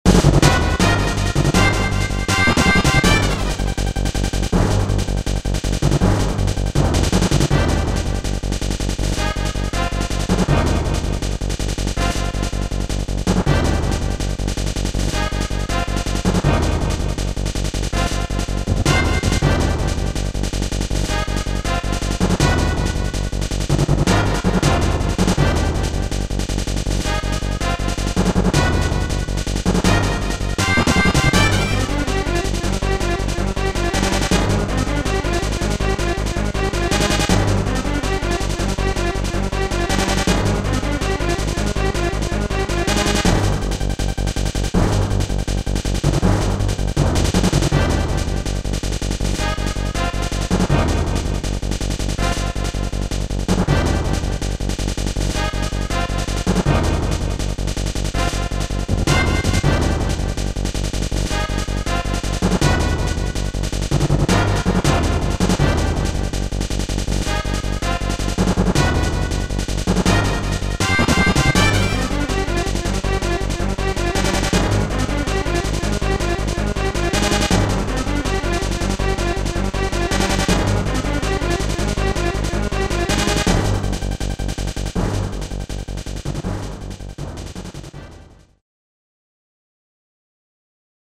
orchestral and rock tunes